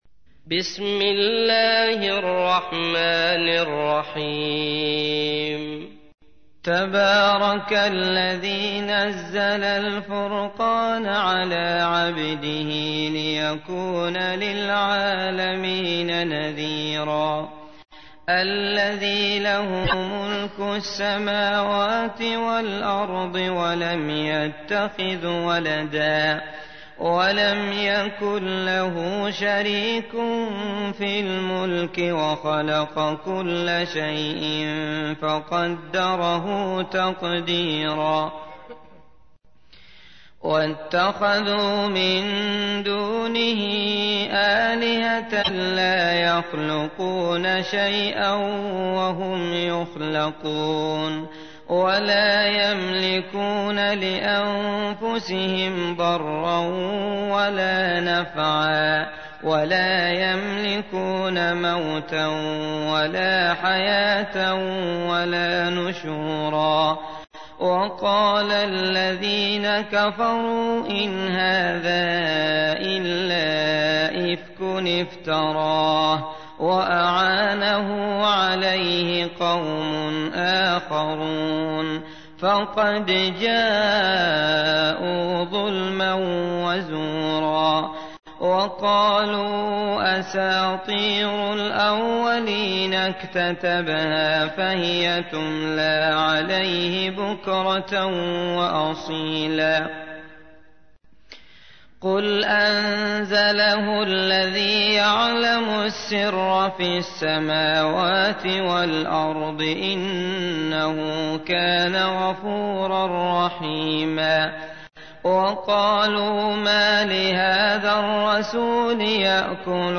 تحميل : 25. سورة الفرقان / القارئ عبد الله المطرود / القرآن الكريم / موقع يا حسين